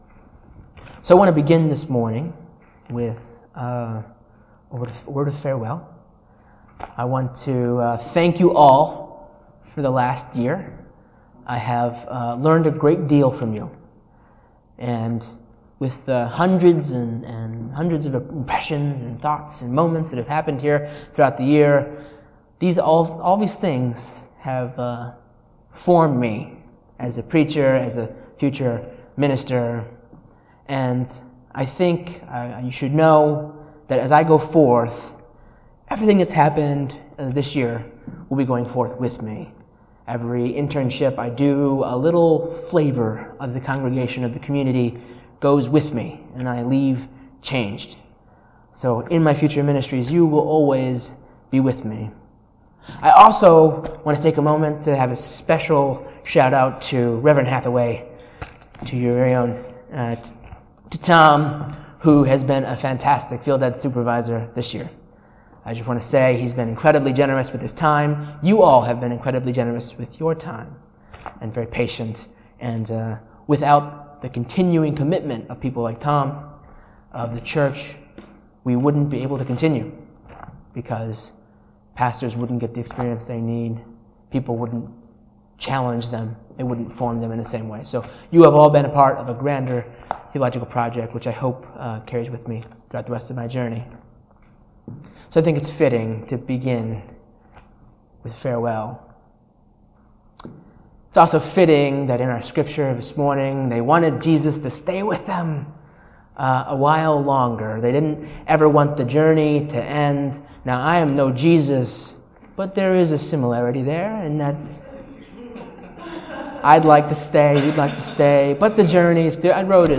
A message from the series "Narrative Lectionary."